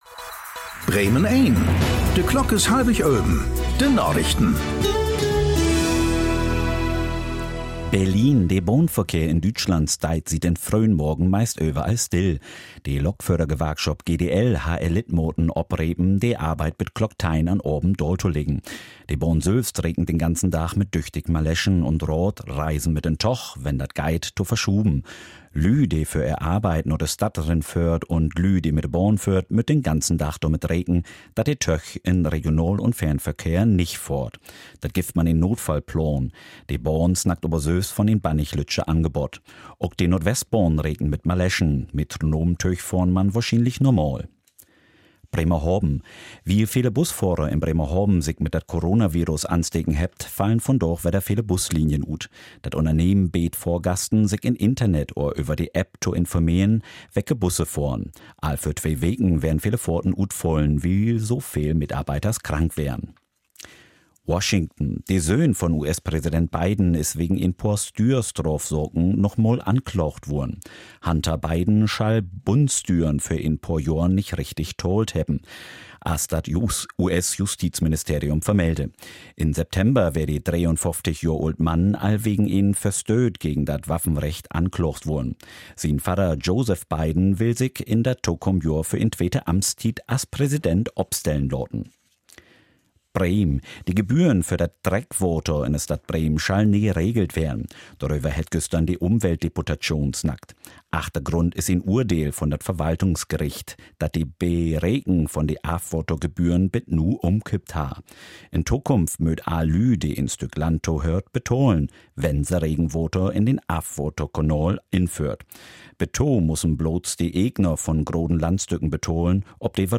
Aktuelle plattdeutsche Nachrichten werktags auf Bremen Eins und hier für Sie zum Nachhören.